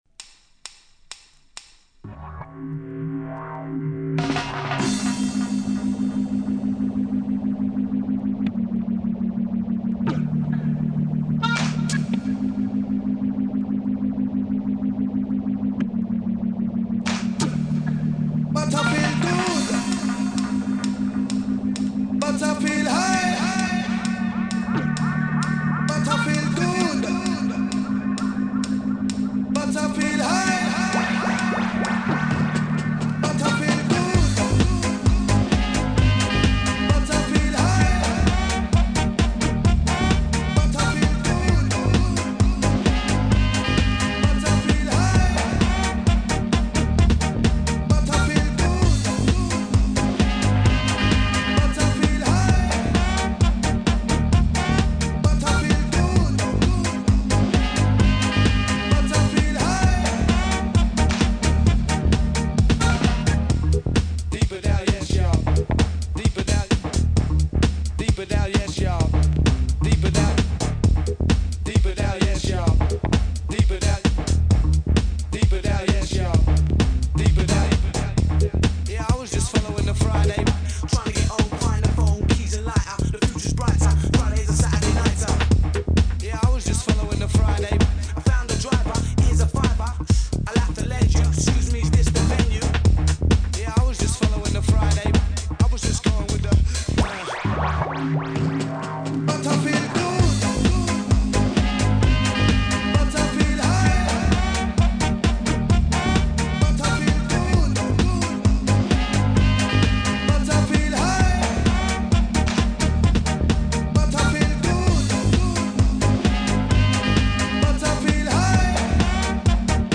Media: Vinyl Only